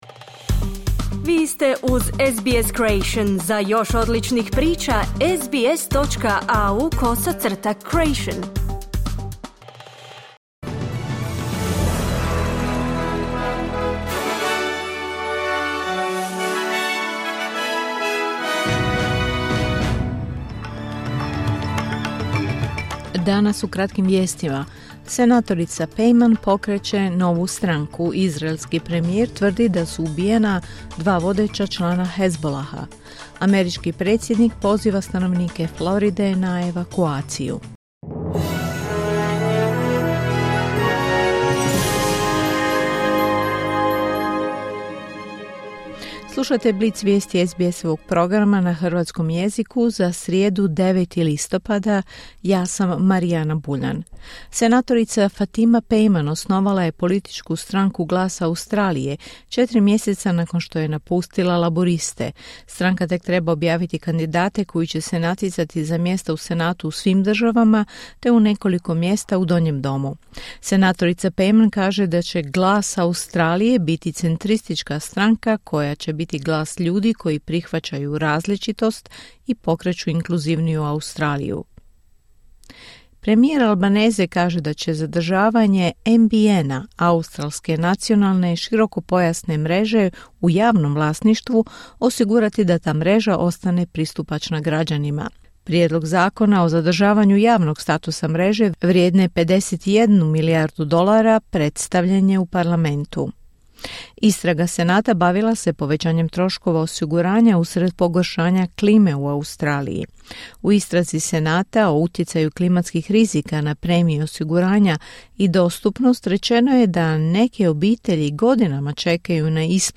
Kratke vijesti SBS-a na hrvatskom jeziku.
Vijesti radija SBS.